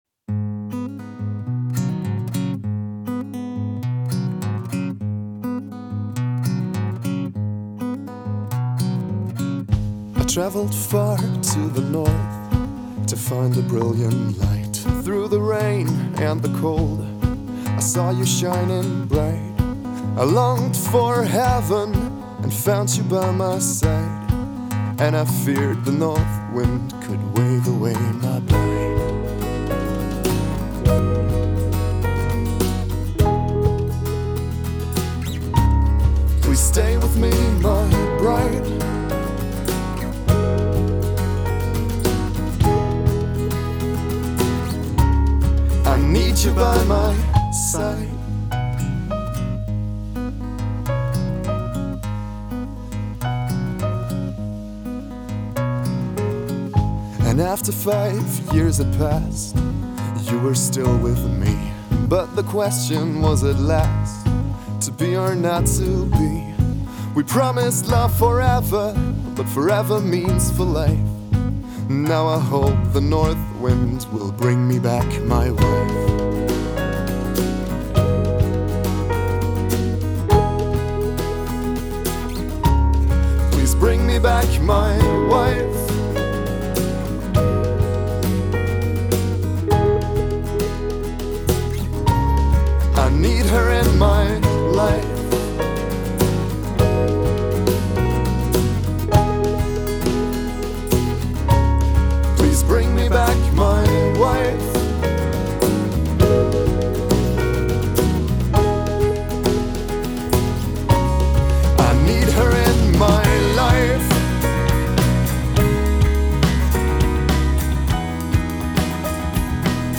Alternative (Folk-Pop)